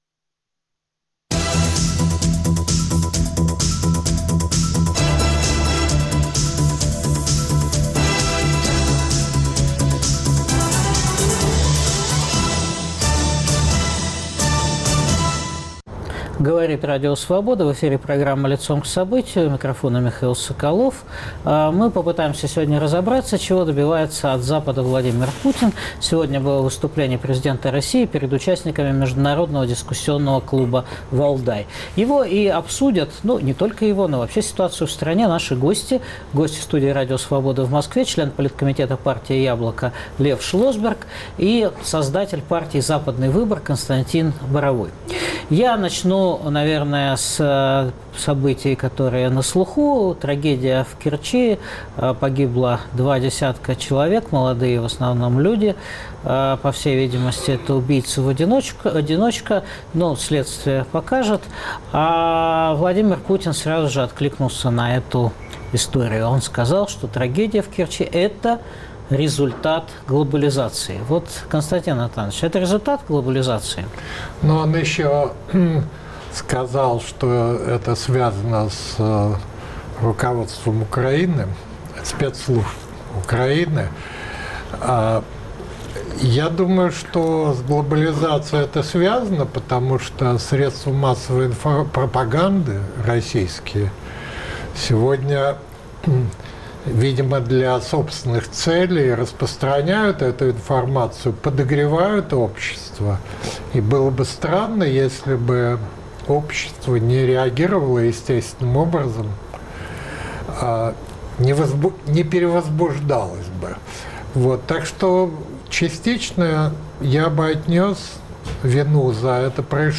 Выступление президента РФ перед участниками Международного дискуссионного клуба "Валдай" обсуждают оппозиционные политики член Политкомитета партии "Яблоко" Лев Шлосберг , Константин Боровой ("Западный выбор").